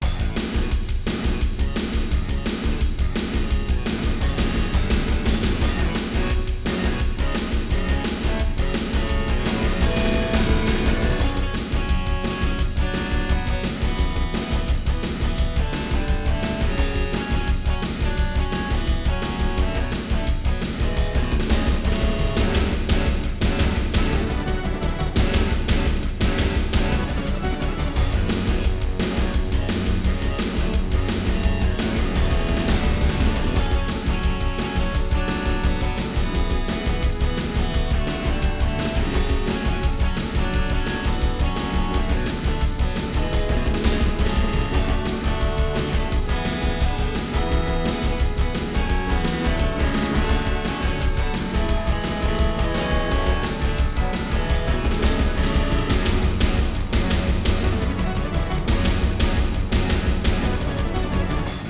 Music: GM midi
Ensoniq Sounscape Elite
* Some records contain clicks.